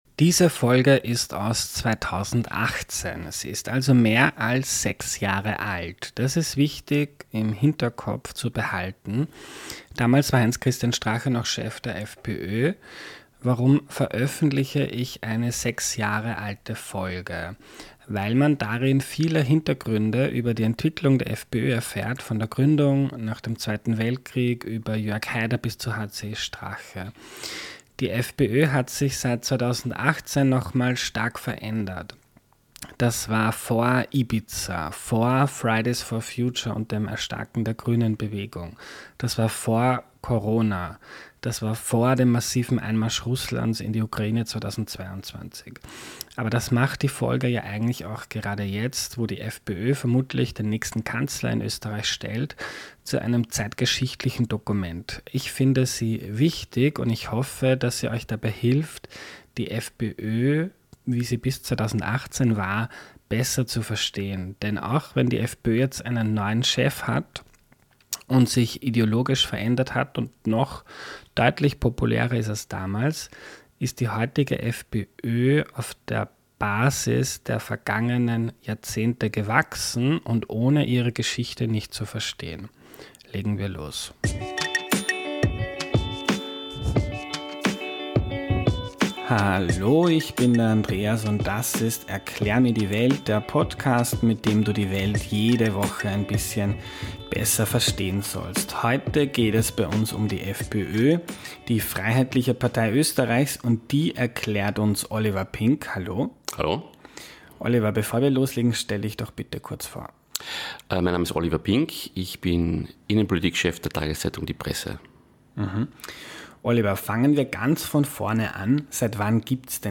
Beatbox am Ende